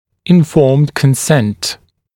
[ɪn’fɔːmd kən’sent][ин’фо:мд кэн’сэнт]информированное согласие